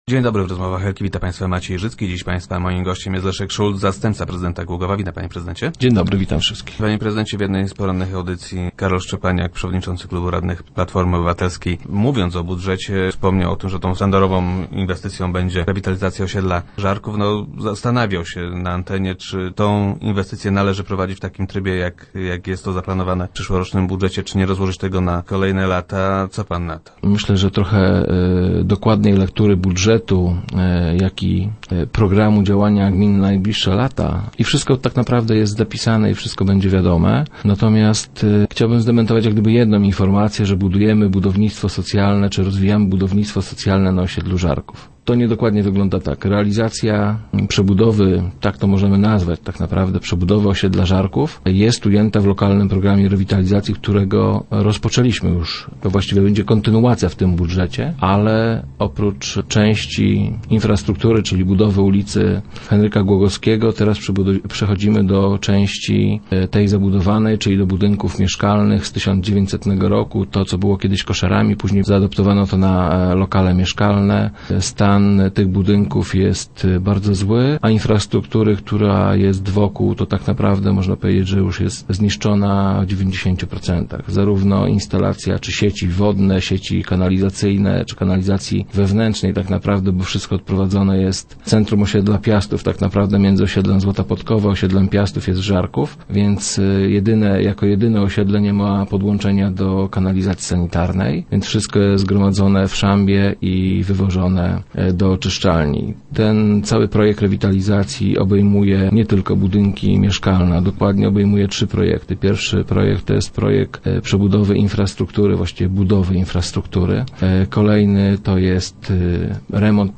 - Będzie to przede wszystkim miejsce, w którym młodzież będzie mogła się spotykać. Młodzi ludzie będą w nim uczestniczyć w różnego rodzaju zajęciach i szkoleniach. To wszystko, co młodzieży jest niezbędne, będzie tam udostępnione. Myślę, że dzięki takiej integracji, poprawią się relacje między rodzinami, które żyją na tych trzech osiedlach - mówił wiceprezydent Leszek Szulc, który był gościem środowych Rozmów Elki.